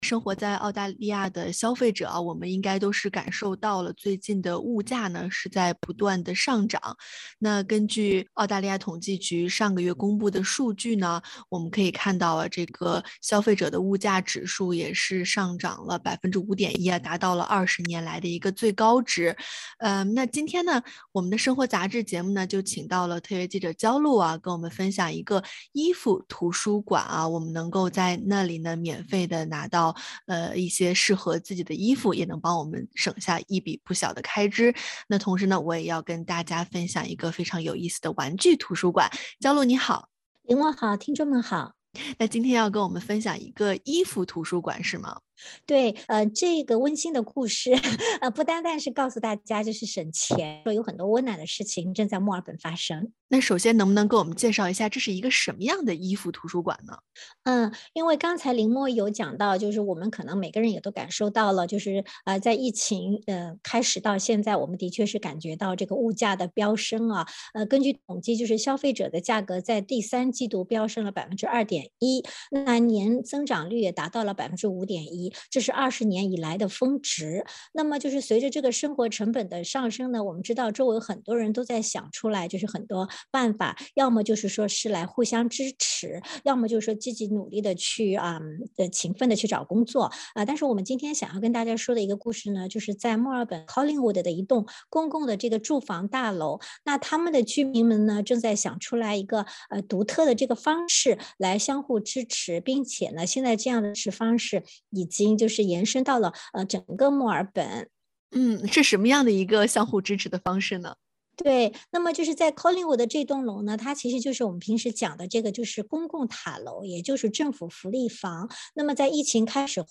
欢迎您点击封面图片，收听完整采访。